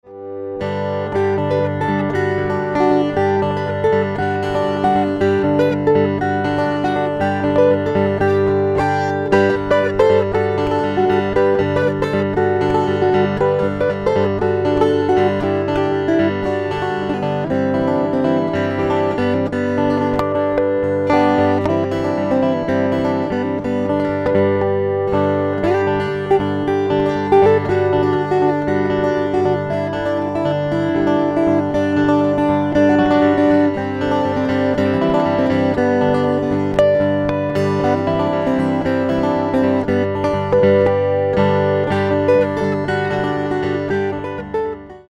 Instrumental (guitar piano)